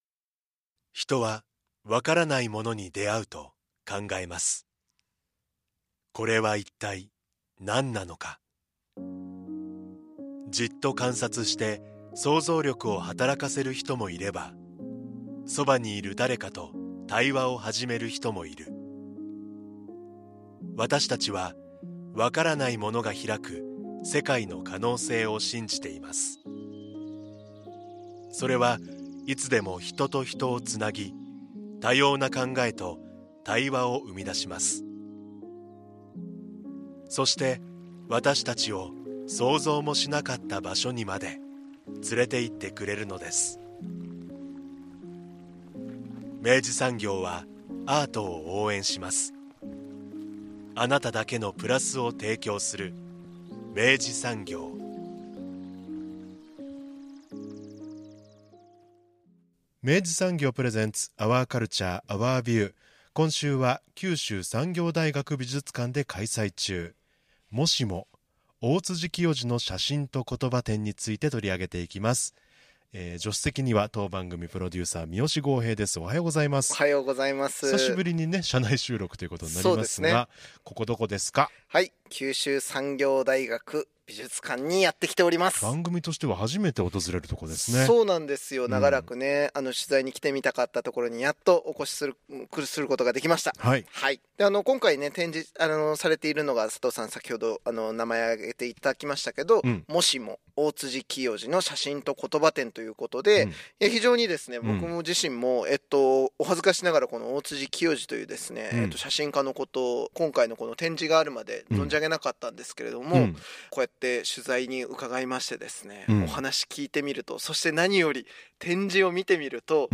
（インタビュー収録日：2024年9月18日）